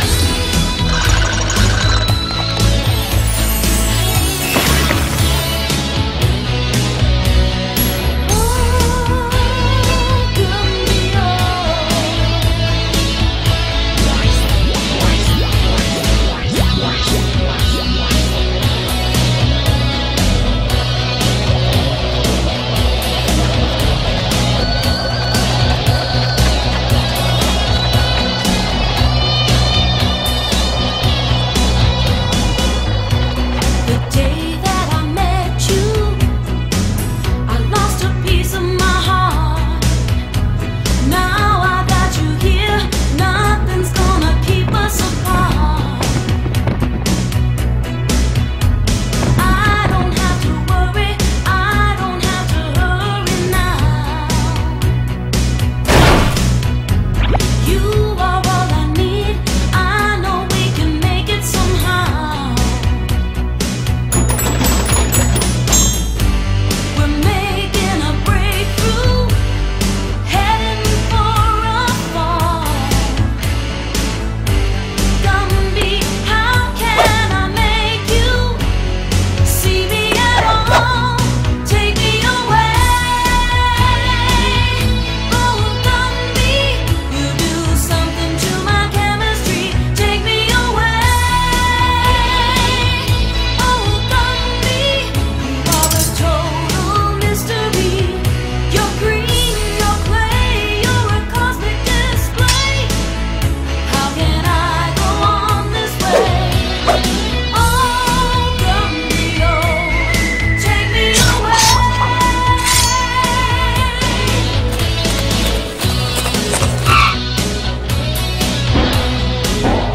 BPM116
MP3 QualityMusic Cut